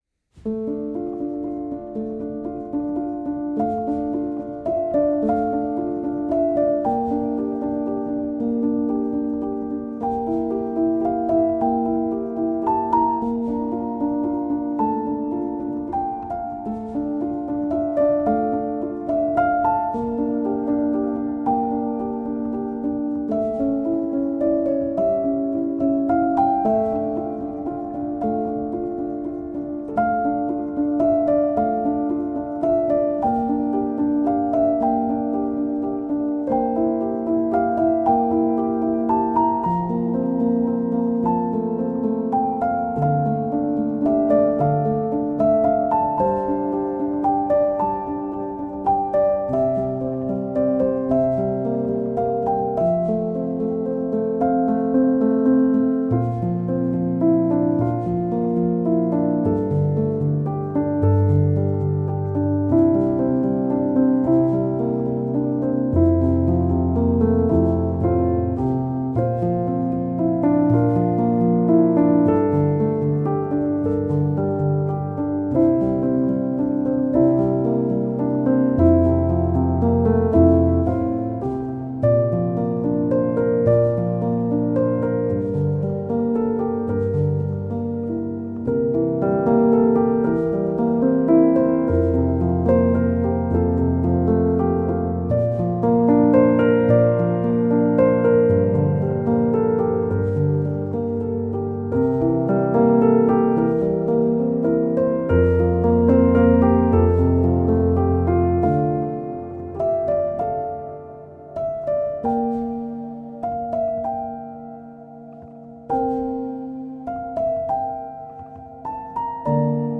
Contemporary Classical Страна